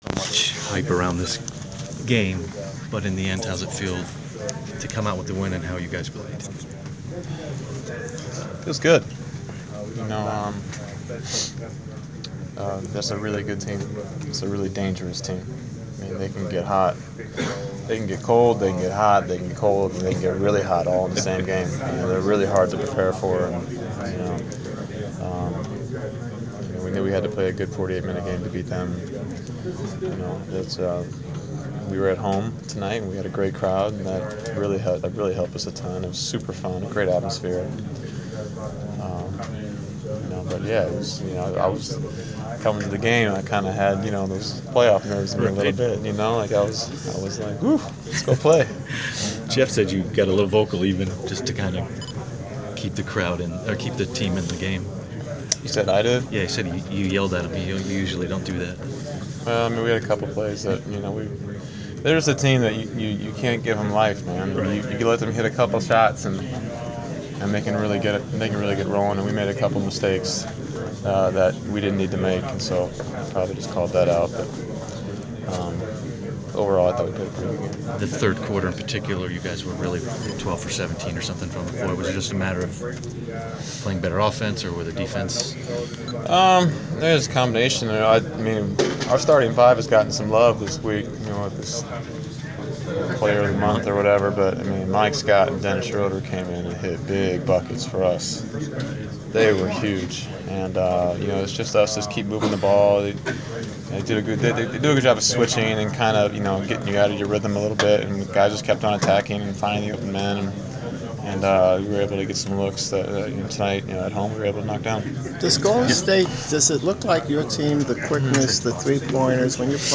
Inside the Inquirer: Postgame interview with Atlanta Hawks’ Kyle Korver (2/6/15)
We interviewed Atlanta Hawks’ guard Kyle Korver following his team’s 124-116 home victory over the Golden State Warriors on Feb. 6.